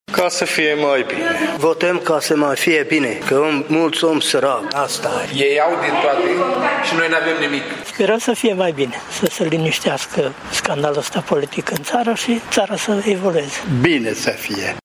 Tîrgumureșenii spun că vor merge la vot, chiar dacă nu sunt convinși că noua clasă politică va aduce schimbarea așteptată de toată lumea: